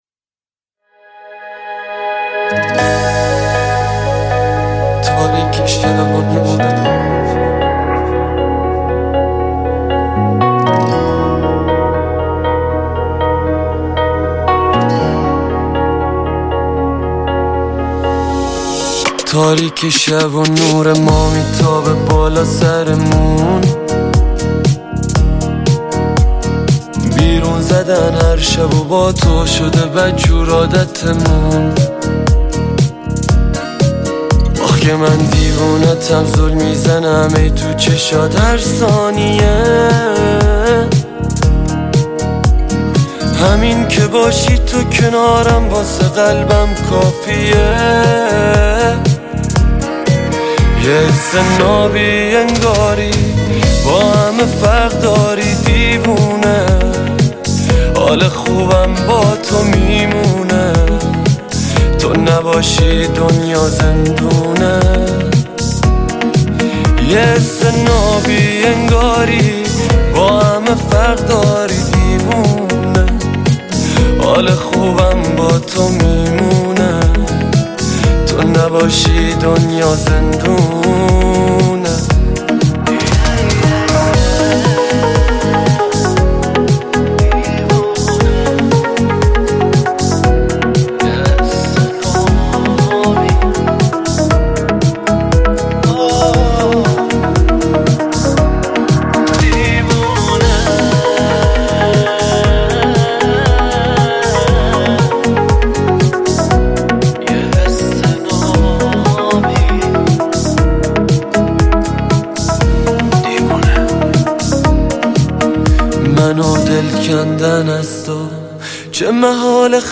موضوعات: تک آهنگ, دانلود آهنگ پاپ